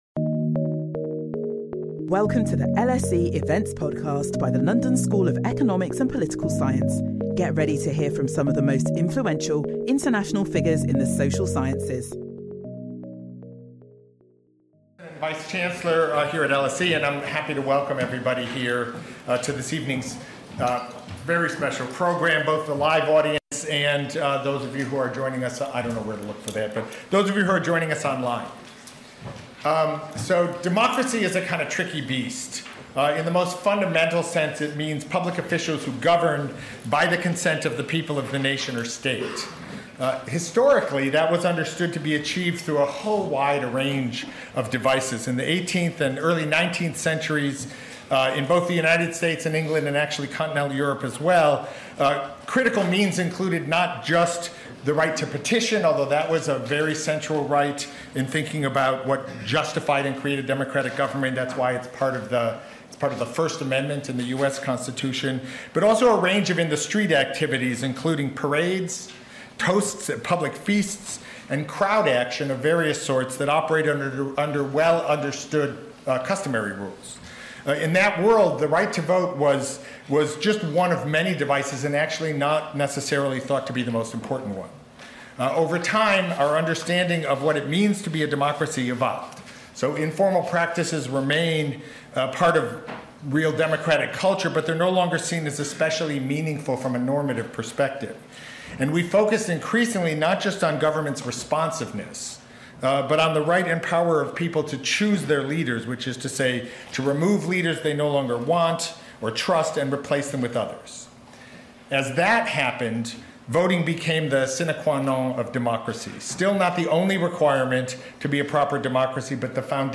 In support of the new project, Nobel Laureates Amartya Sen and Eric Maskin address core democratic principles. Professor Sen revisits the foundational ‘rule by the people’ with his talk, Democracy—Why, and Why Not?